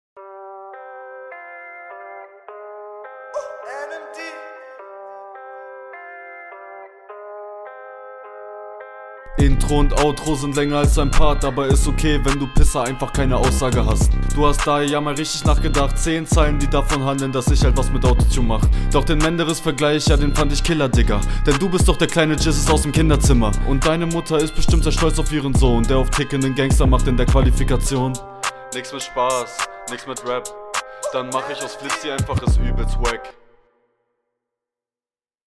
wie kann man so schlecht kontern und raptechnisch deutlich schlechter die gegner hr war nicht …